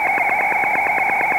Help! with Heart Rate sound file analysis
These are files recorded from a transmitter and include heart beats of a wild bird.
There is too much background noise and the heart rate is not always the loudest sound in in a given second etc. Is there way to better fine tune a file so that it will amplify a certain frequency to be the loudest? or a certain band of frequencies?
This has a lot to do with inconsistent sound recording from the transceiver as the animal moves further or closer away from the antenna etc. I’ve attached a partial file to this post.